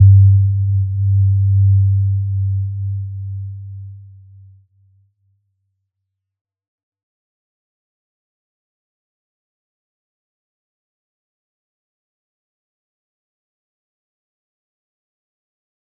Little-Pluck-G2-p.wav